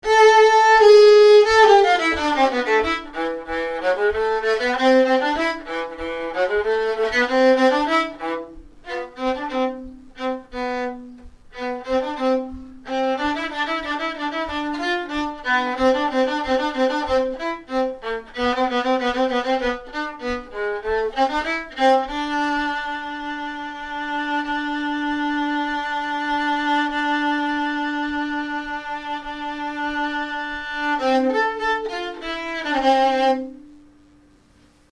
A little bit of Vivaldi on the Octave Violin